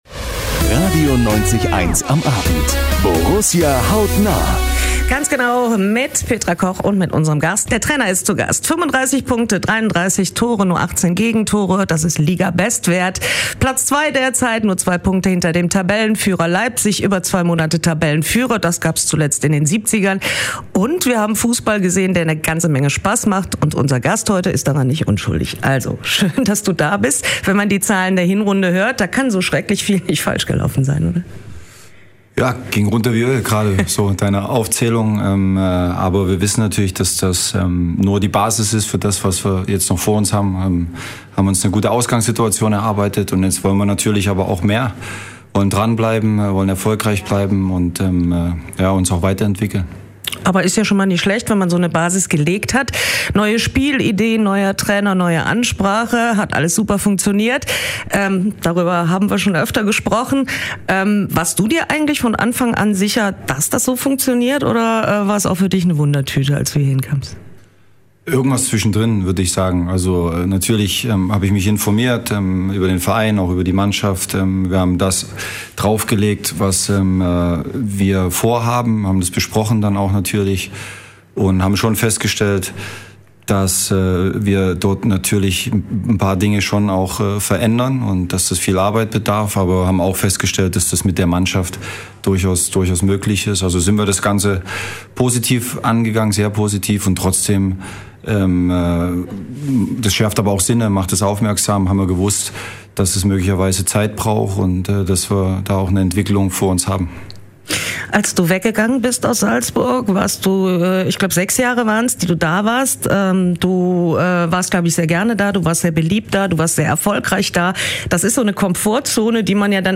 Vor dem Rückrundenstart war Borussen-Trainer Marco Rose bei RADIO 90,1. Im Gespräch lernt ihr den Coach hautnah kennen.